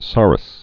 (särəs)